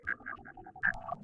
Grabcrab_idle2.ogg